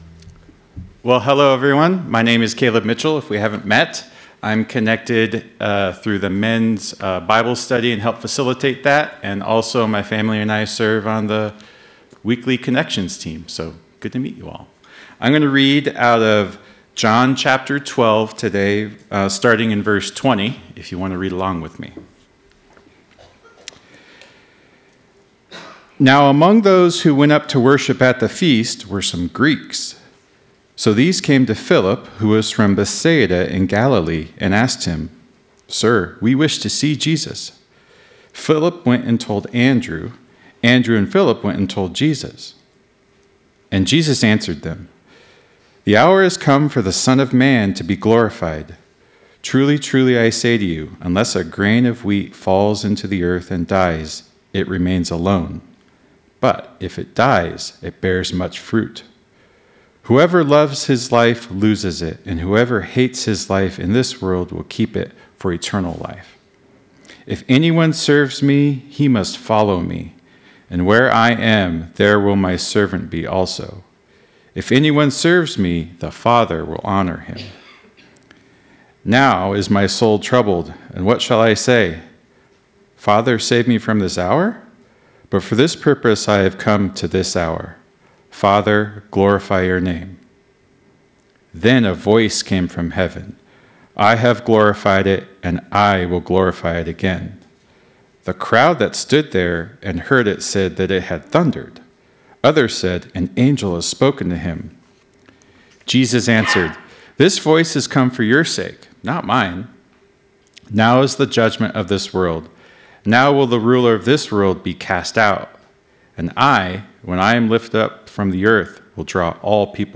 a thoughtful conversation through John 11, the story of Lazarus. Why did Jesus wait when the one He loved was sick?